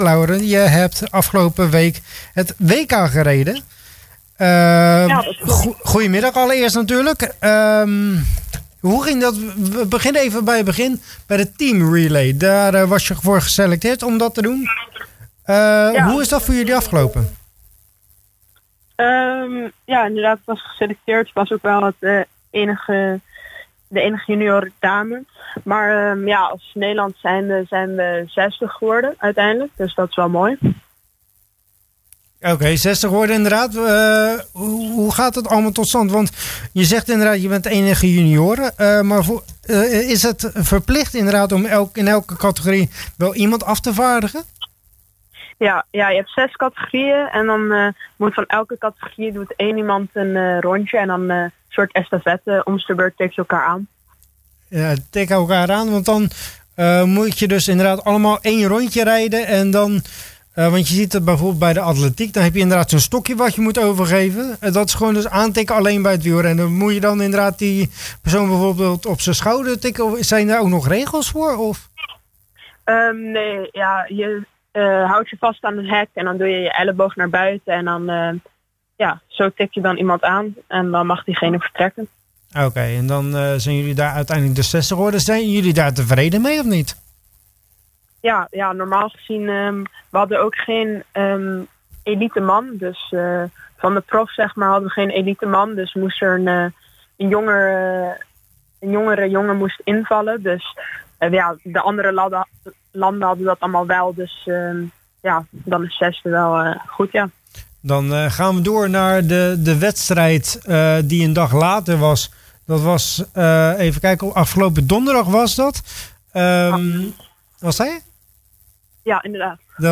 Wij vroegen aan haar tijdens IJssel-Sport hoe haar dat is vergaan en we begonnen bij de teamrelay.